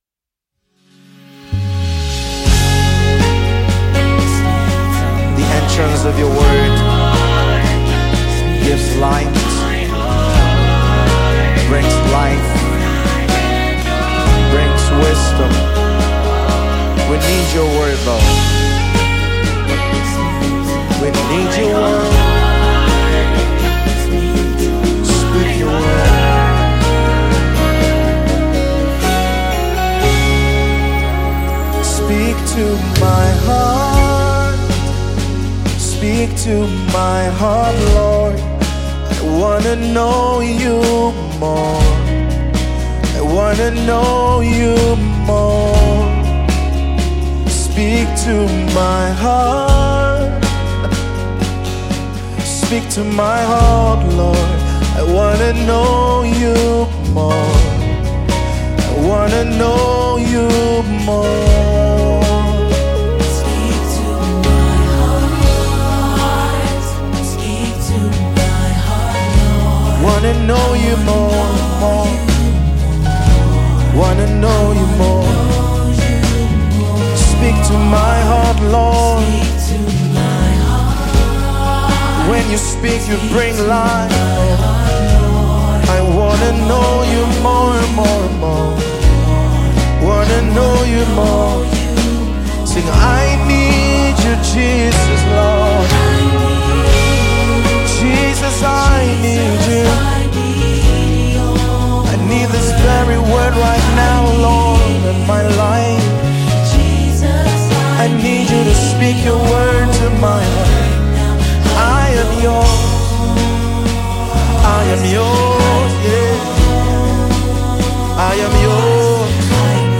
by Gospel singer